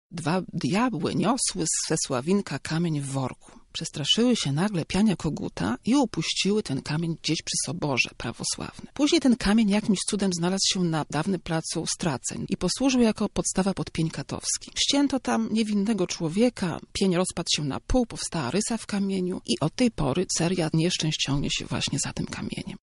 mówi przewodnik miejski